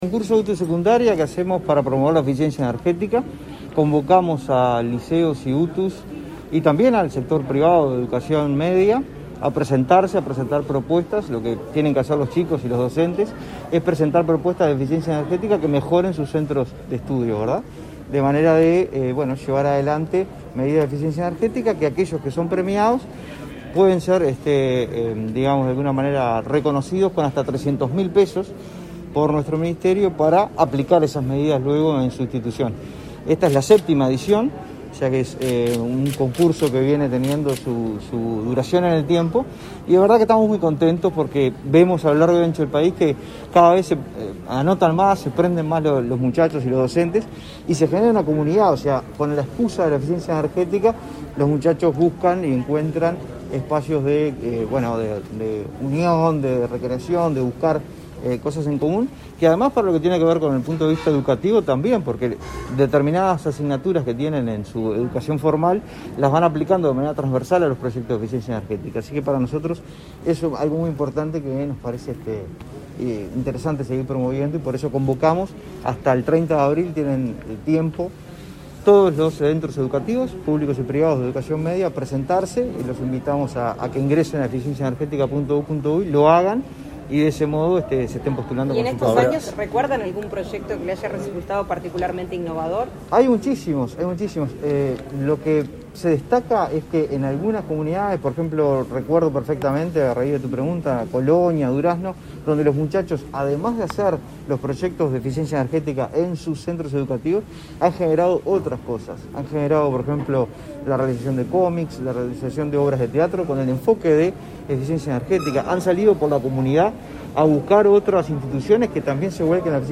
Declaraciones a la prensa del director nacional de Energía
El director nacional de Energía, Fitzgerald Cantero, participó este martes 29 en Montevideo del lanzamiento del Concurso de Eficiencia Energética,